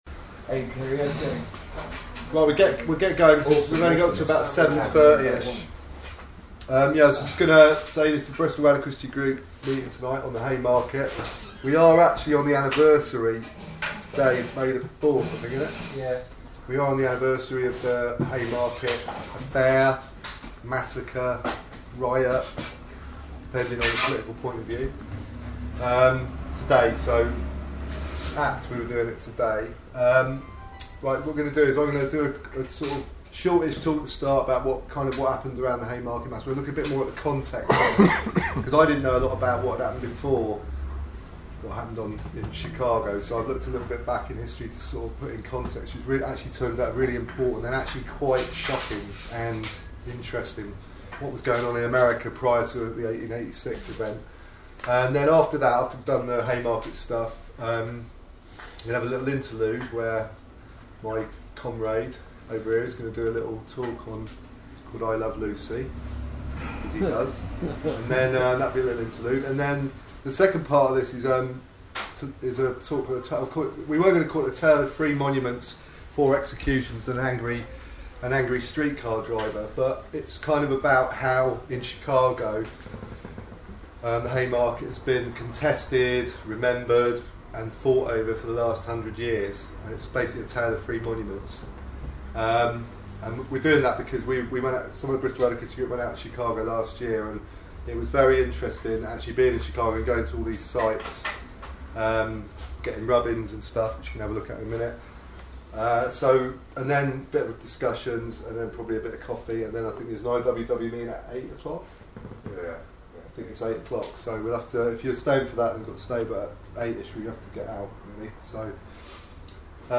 May 1st became workers day, labour day, Mayday commemorating the incident in the Haymarket in 1886. This talk will look at the history of the events, the strange narratives of the memorials and how Mayday is remembered in Chicago.